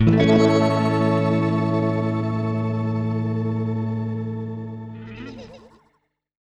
GUITARFX 4-L.wav